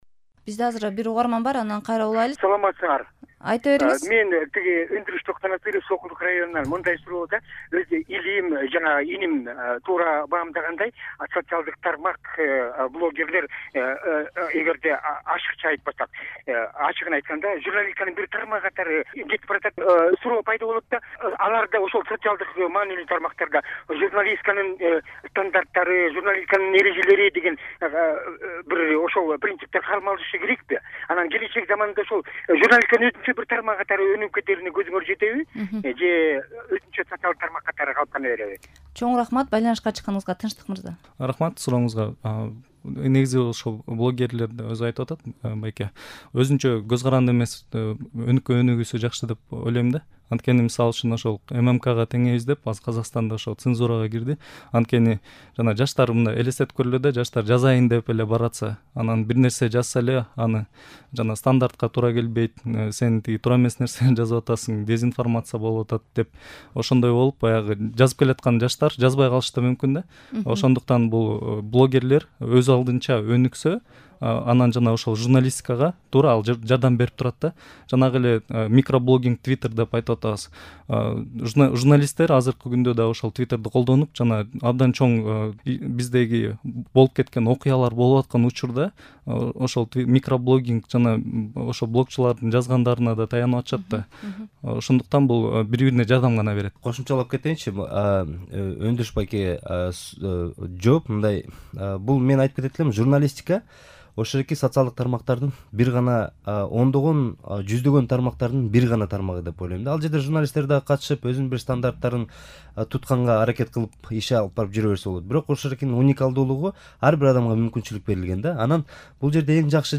Талкуунун биринчи бөлүгү